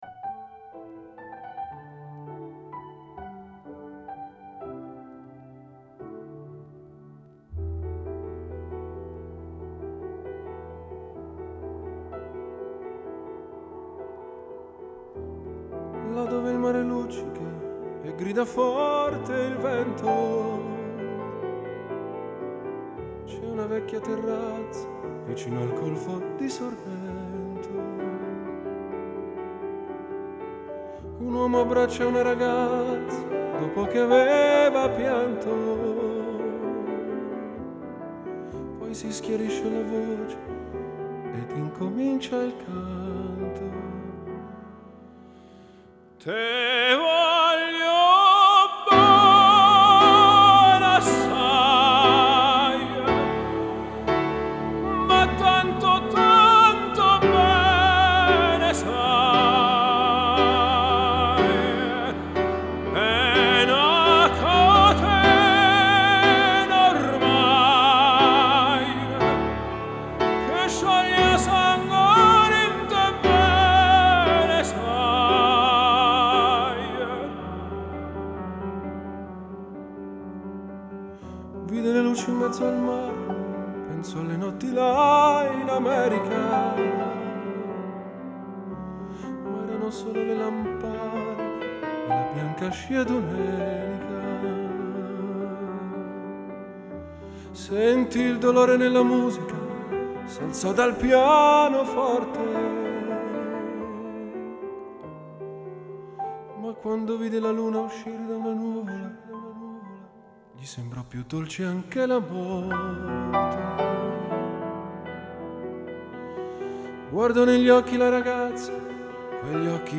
Стили: Pop/Classical/Vocal/Crossover